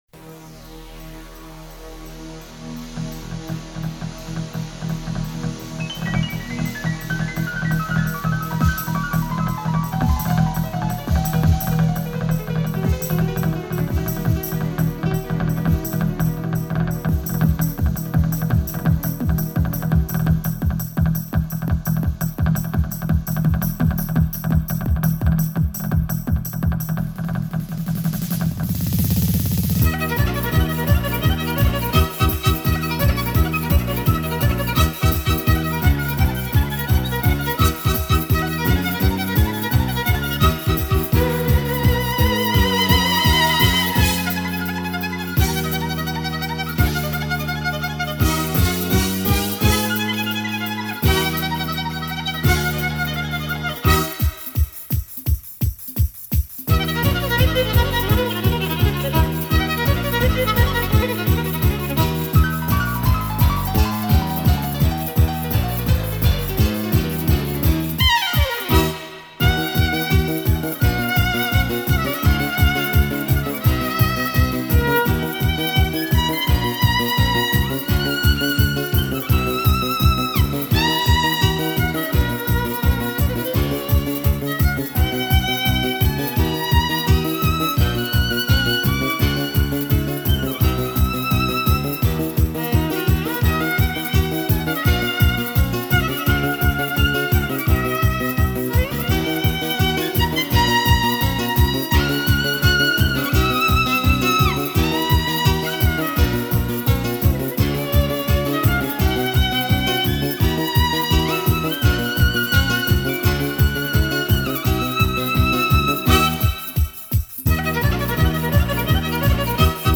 در سبک پاپ
ویلن (سلو)/گیتار
پیانو/کیبورد
گیتار الکتریک
ضبط: استودیو پاپ
(بی کلام)